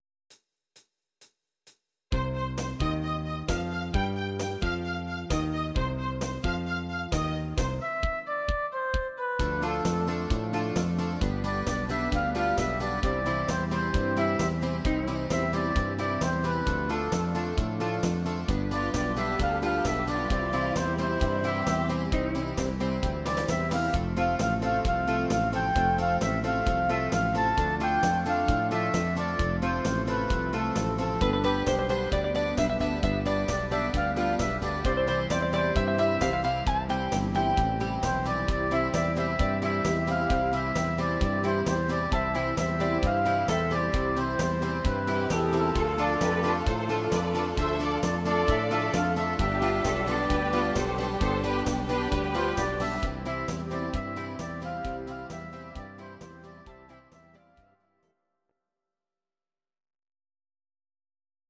Audio Recordings based on Midi-files
Country, 1970s